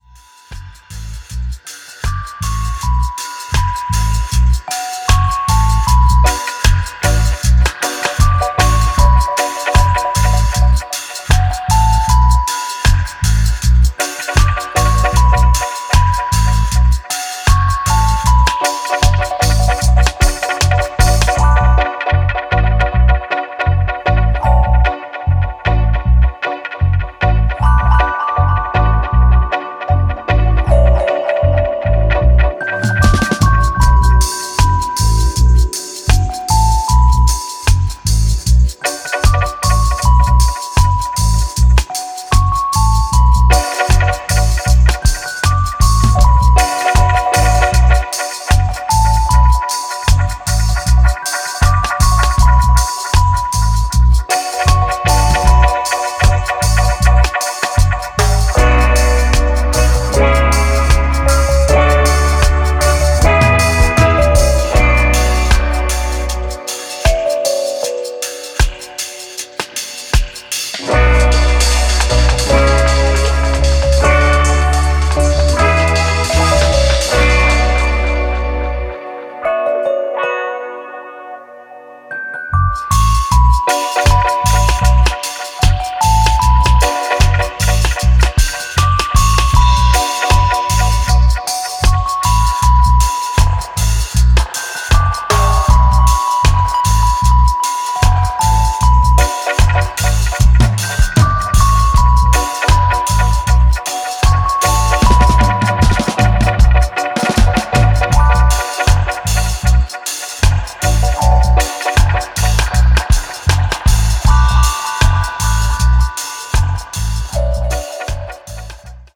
Reggae/Dub